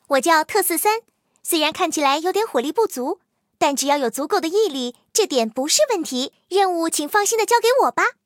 T43登场语音.OGG